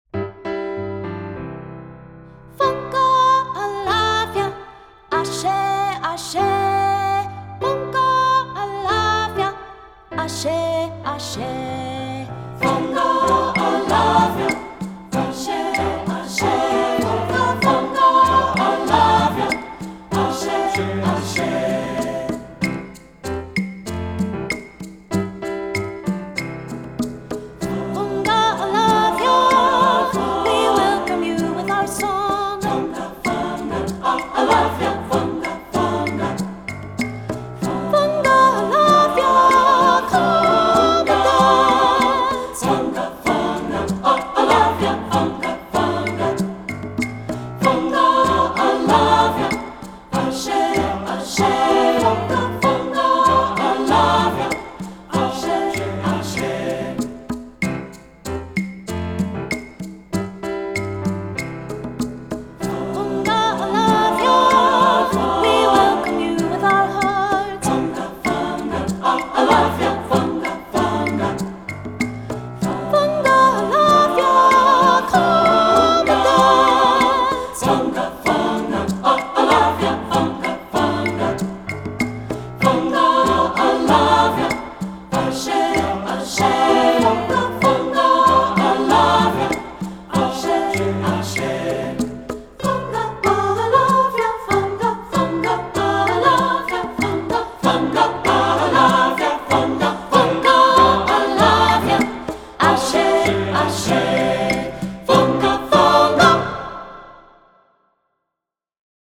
Composer: Nigerian Folk Song
Voicing: 3-Part Mixed and Piano